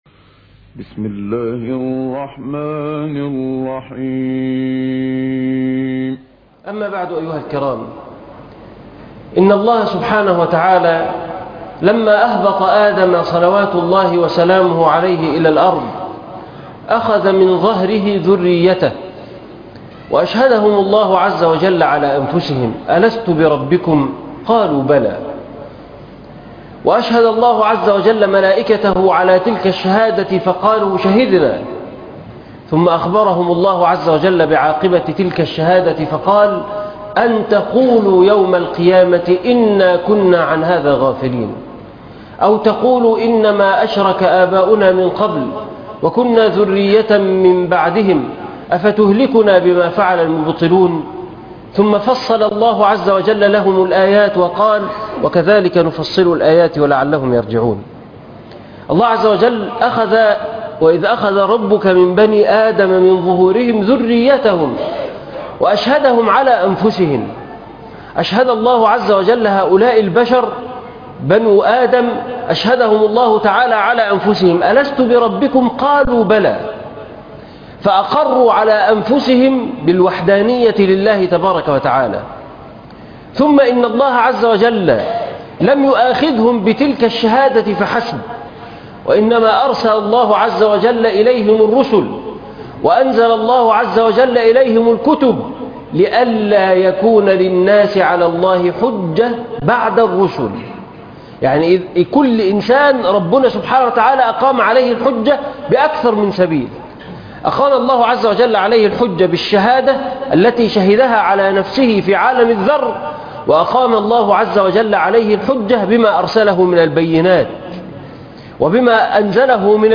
ولا تقربوا الفواحش- خطبة جمعة